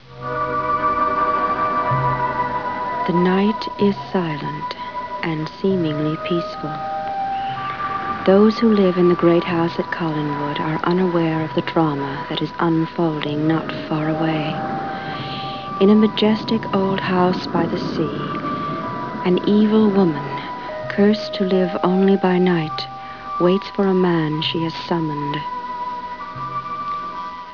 [6] Lara & Angelique Speak!  ( Wave files )
Snd.  - Lara introduces D.S. episode 574.   [ 296 KB ]